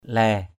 /lɛ:/ 1.